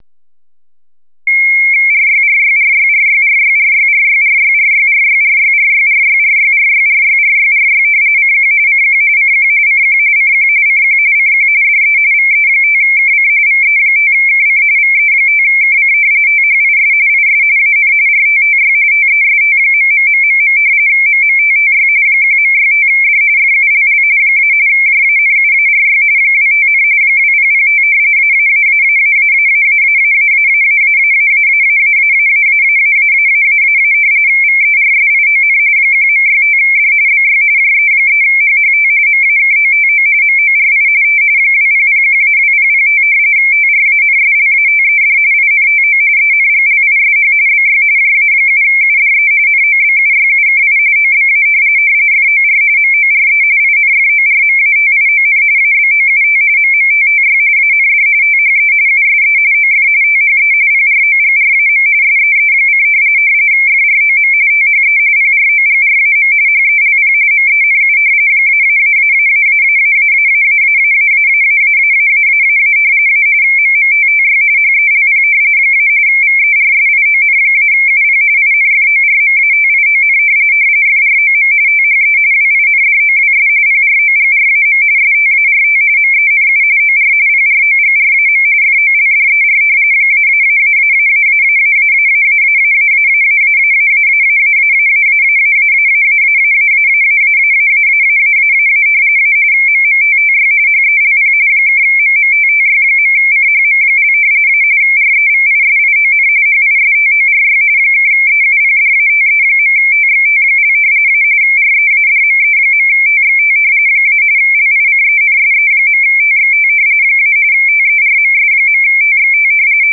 rtty_test-1.wav